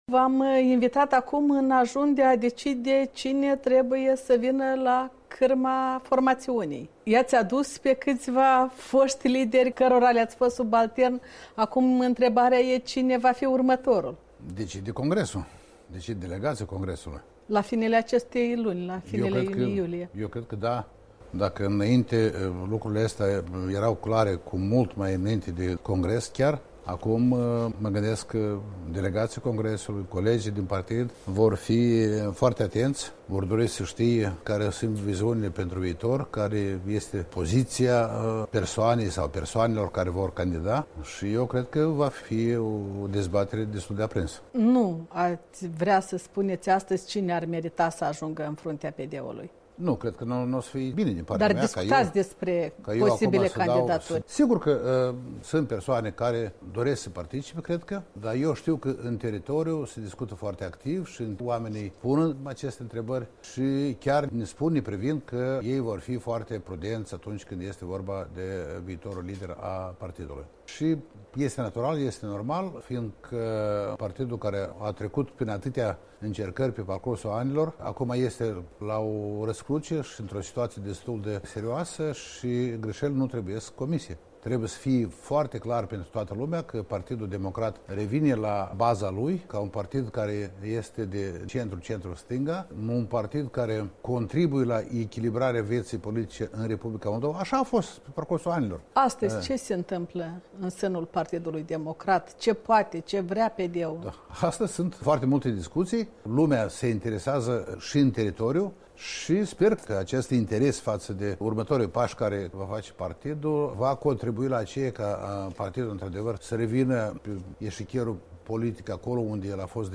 Interviu cu Dumitru Diacov